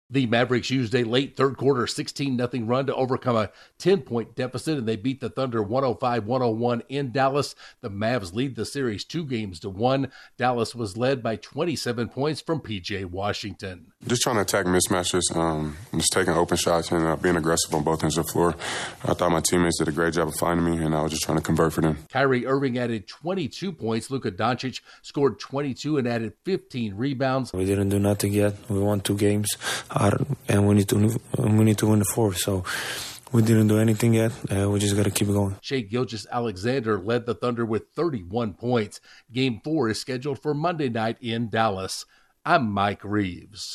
The Mavericks use a third-quarter run to take the lead in their Western Conference semifinal series. Correspondent